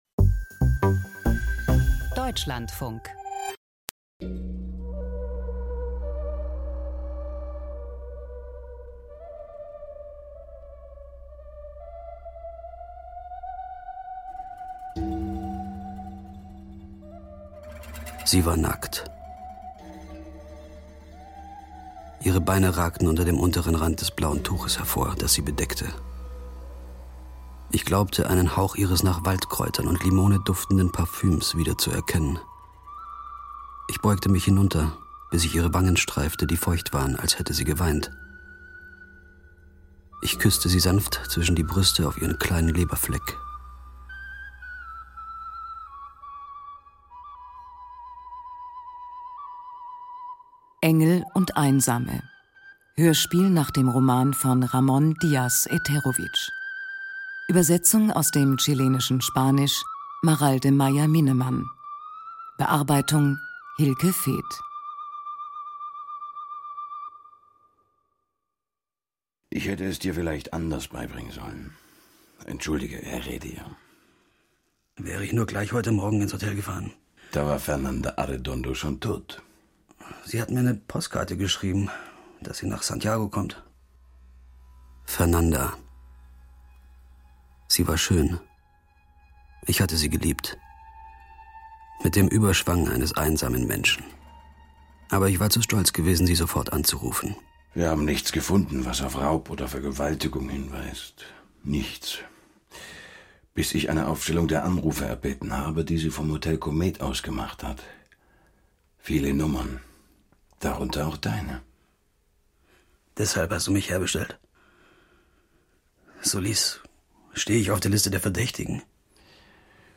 Krimi-Hörspiel: Illegale Waffengeschäfte in Chile - Engel und Einsame